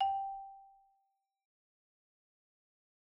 Marimba_hit_Outrigger_G4_loud_01.wav